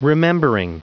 Prononciation du mot remembering en anglais (fichier audio)
Prononciation du mot : remembering